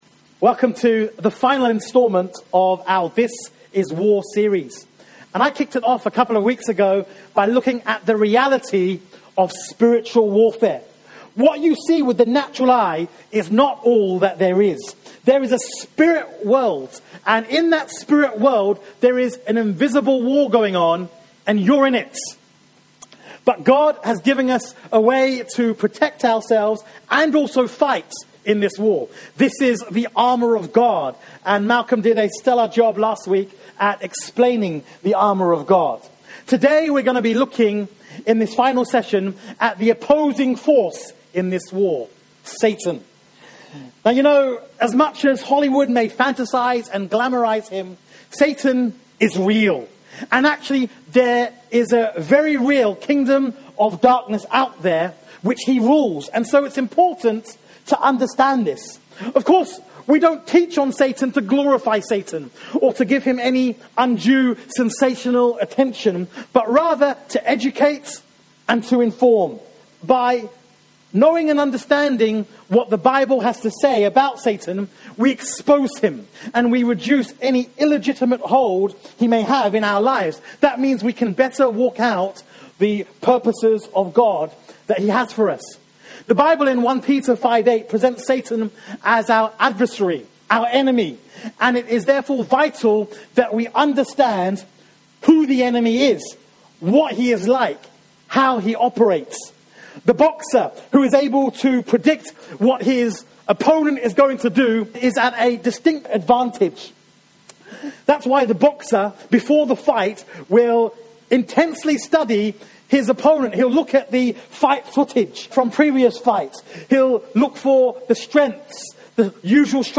By knowing what the Bible says about Satan, we expose him and reduce any illegitimate hold he has on our lives. This sermon provides lots of valuable insight on what the Bible reveals about Satan - who he is, what he is like and how he operates.